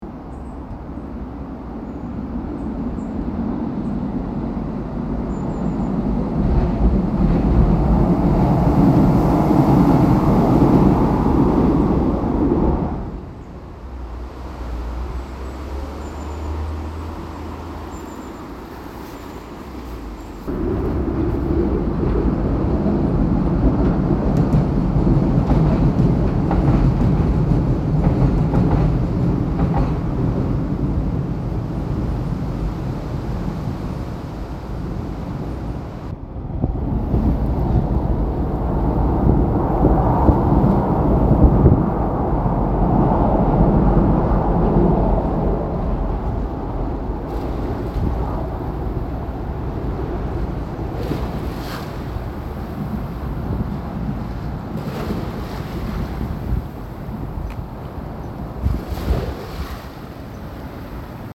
Residents of South Queensferry (and North Queensferry) are familiar with the sonic "rumble" heard when a train passes over The Forth Bridge. The submitted audio recording includes 3 such rumbles and some ambient sounds such as waves lapping on the beach under the bridge. Unfortunately 30th December 2024 when the recording was taken was a very windy day so the mobile phone recording is not very clear, but the wind illustrates the weather conditions that the bridge has endured over its 134 year lifetime (so far).